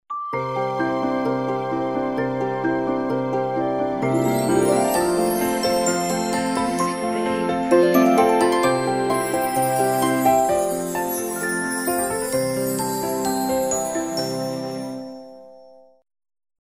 Royalty free music for video.